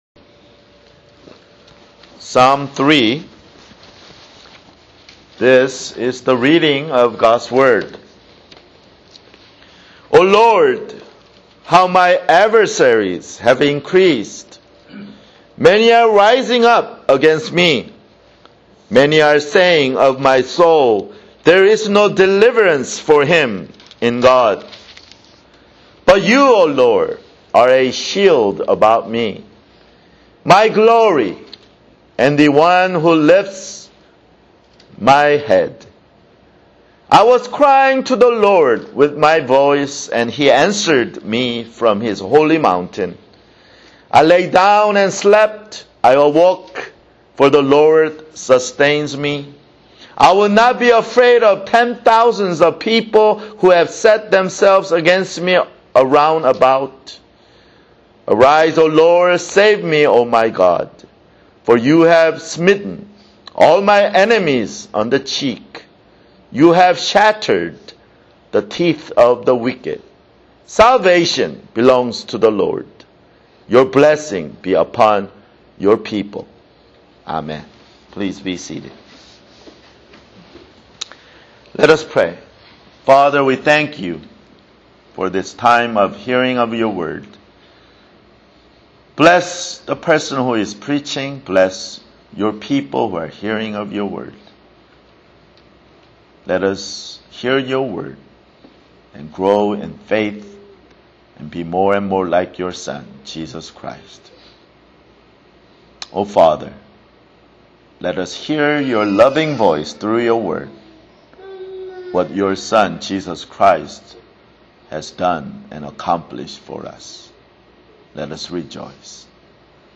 [Sermon] Psalms 3
Download MP3 (Right click on the link and select "Save Link As") Labels: Sermon - Lord's Supper